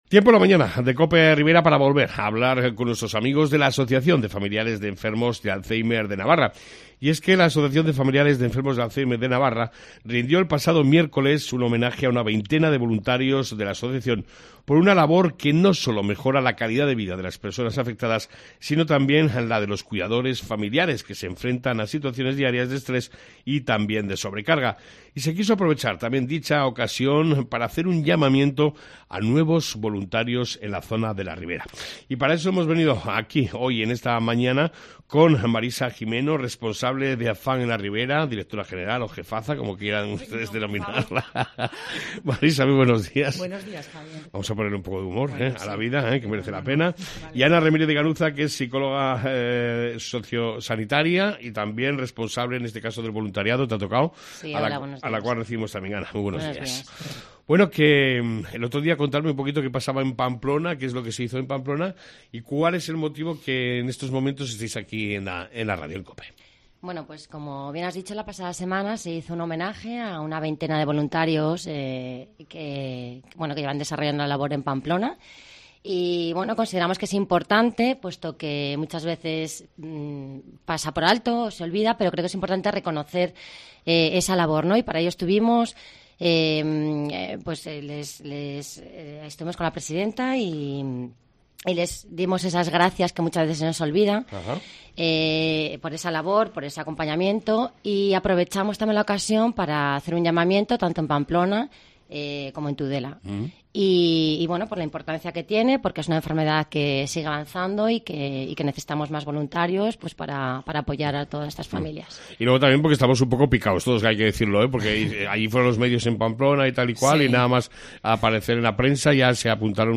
ENTREVISTA CON AFAN TUDELA Y RIBERA